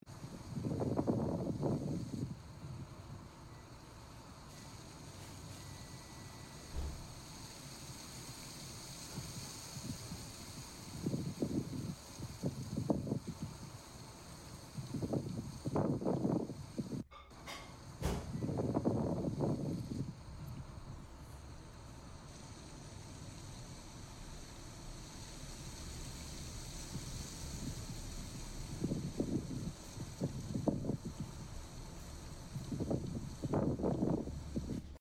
The ice coated branches tinkle gently in the wind.